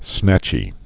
(snăchē)